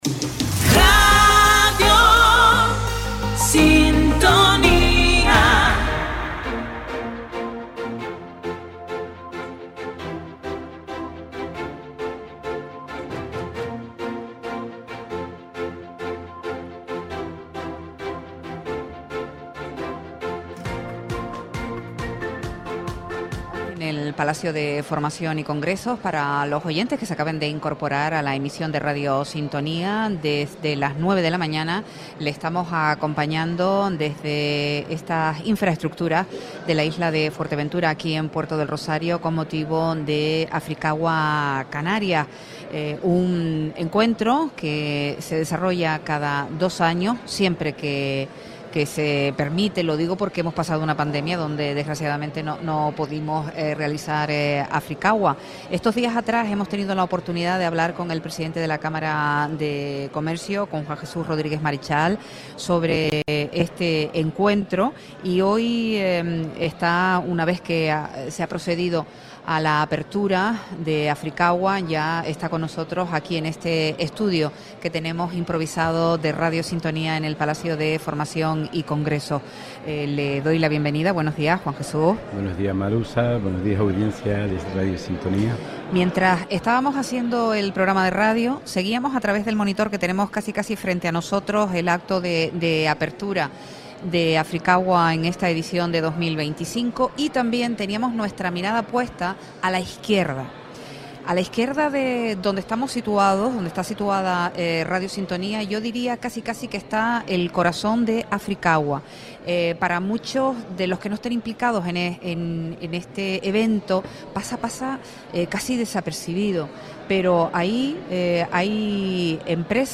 Radio Sintonía emitió este miércoles un programa especial desde el Palacio de Formación y Congresos de Puerto del Rosario, con motivo de una nueva edición de Africagua Canarias, el foro internacional de referencia sobre agua, energías renovables y colaboración entre territorios.
Entrevistas